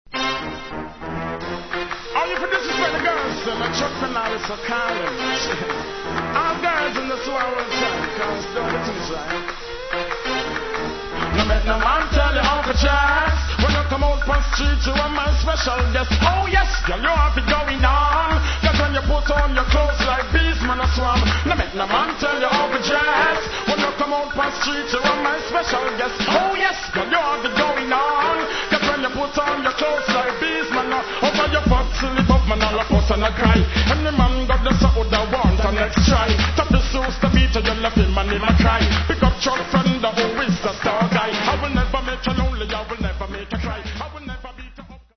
its more like a dancehall version